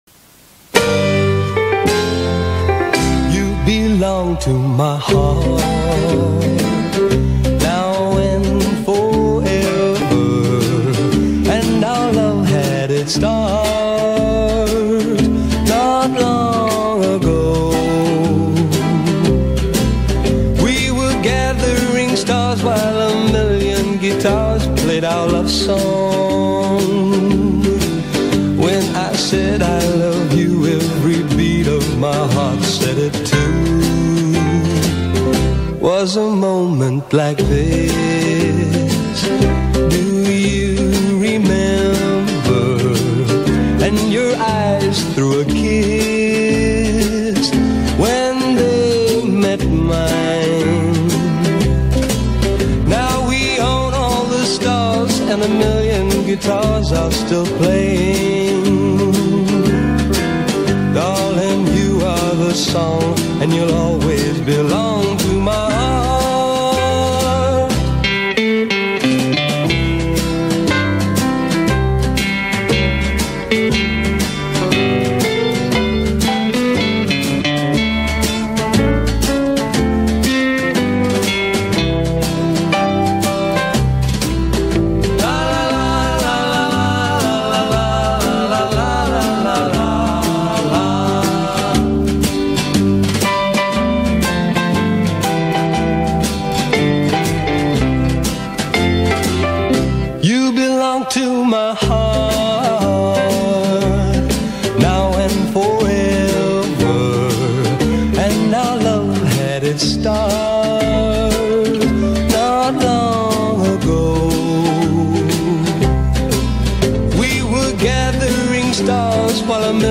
Solo Recorder